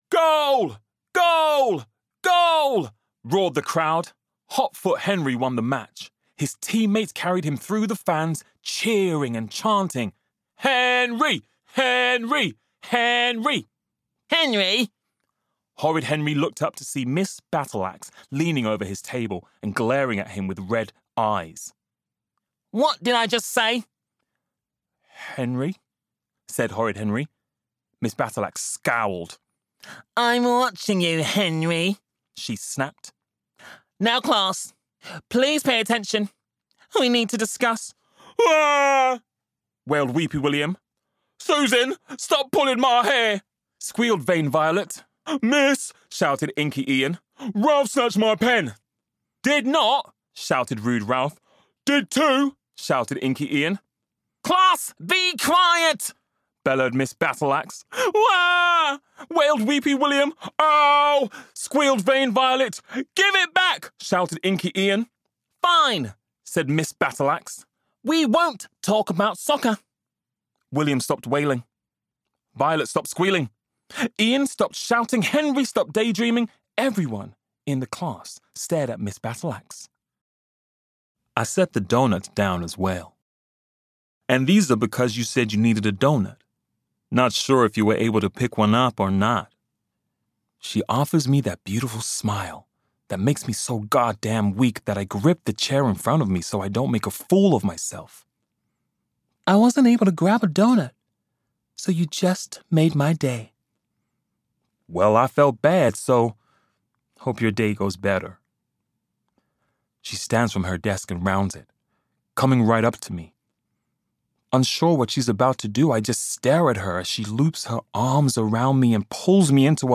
• Native Accent: London